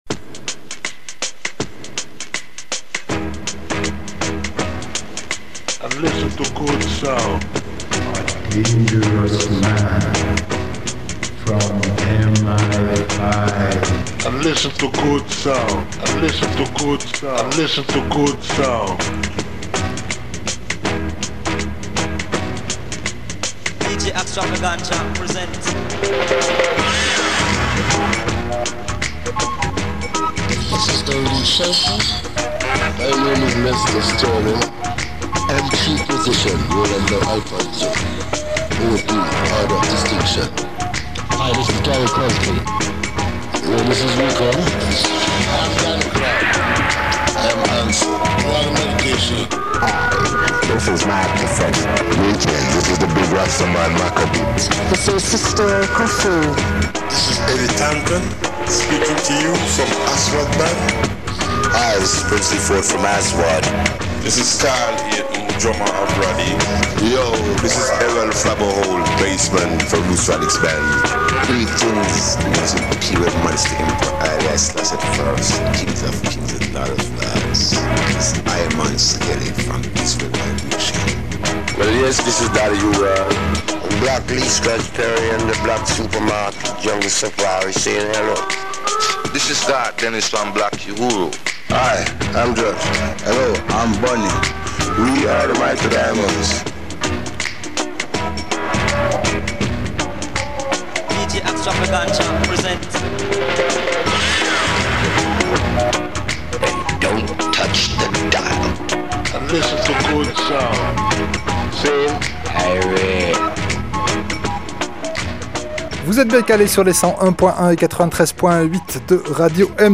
radio show !